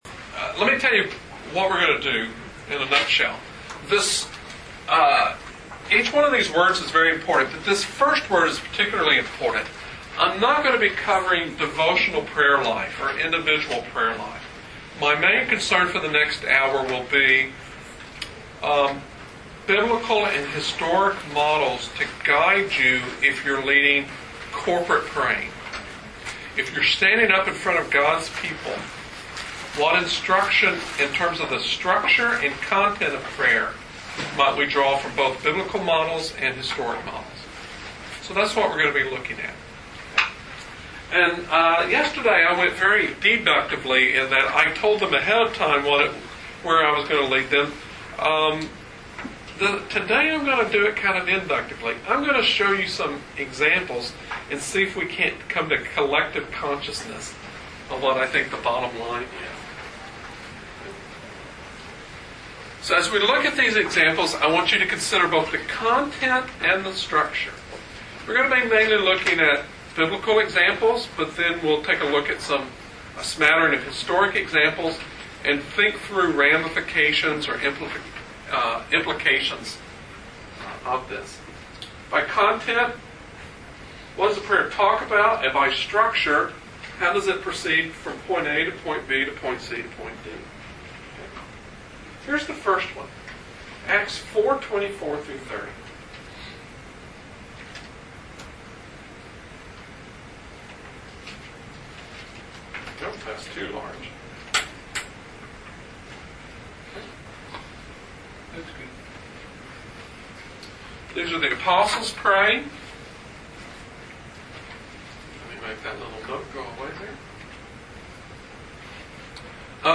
Presented at the 2008 Calvin Symposium on Worship.